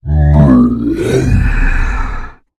spawners_mobs_balrog_death.3.ogg